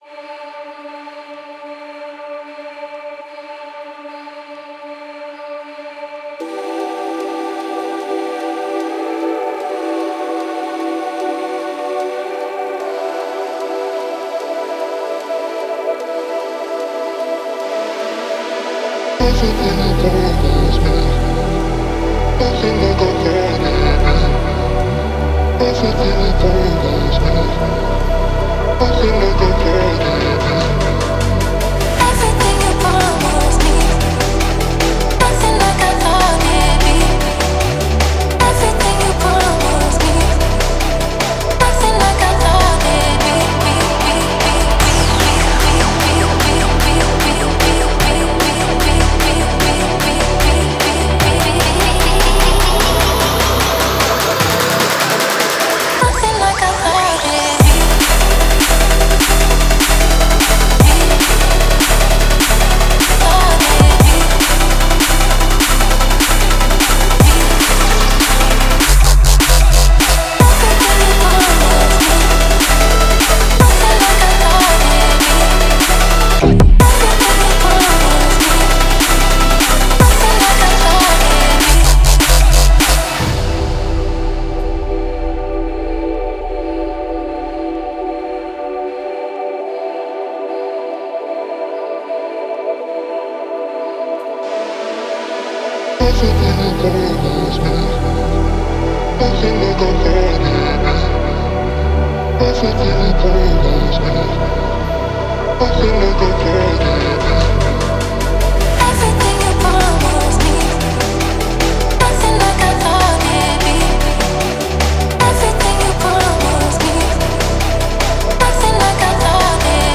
Drumstep, Dark, Gloomy, Epic